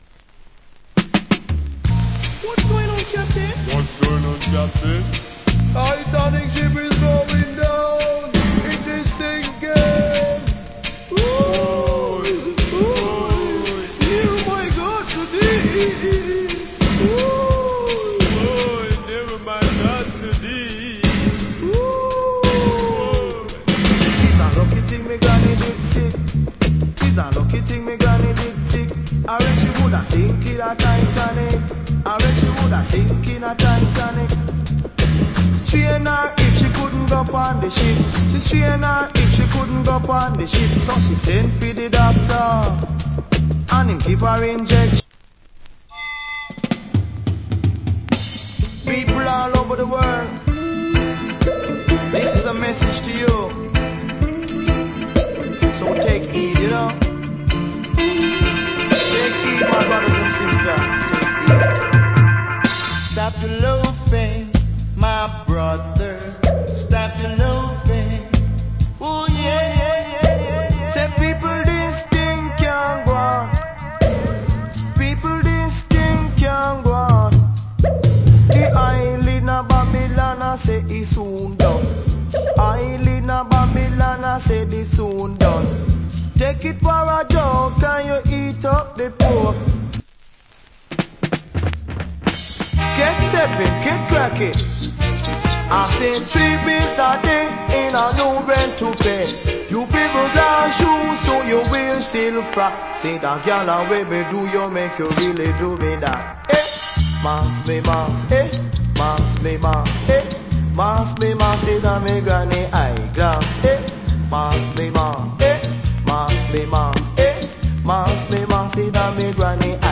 Nice DJ.